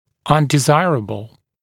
[ˌʌndɪ’zaɪərəbl][ˌанди’зайэрэбл]нежелательный